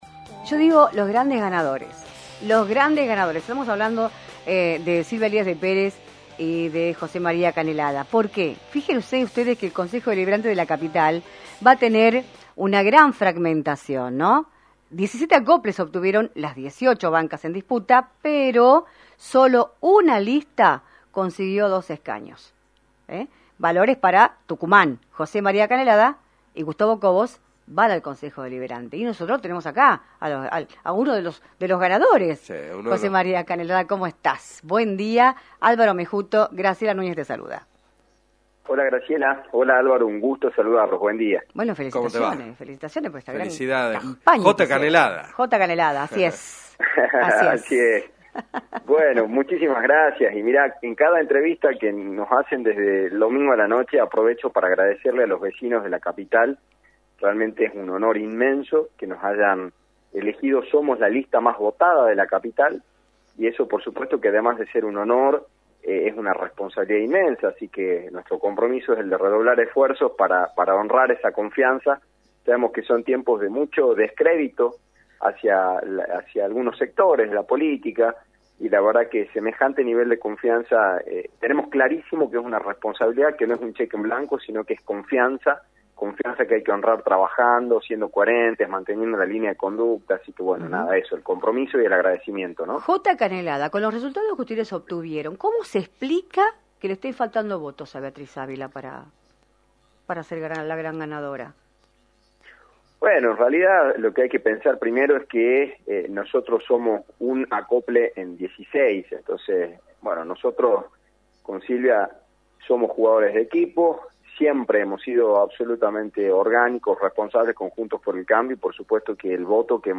José María Canelada, Legislador y Concejal electo de San Miguel de Tucumán, analizó en “Libertad de Expresión” por la 106.9, el desarrollo y los resultados de las elecciones provinciales que se llevaron a cabo el domingo 11 de junio  y denunció irregularidades tanto en el escrutinio provisorio como en el definitivo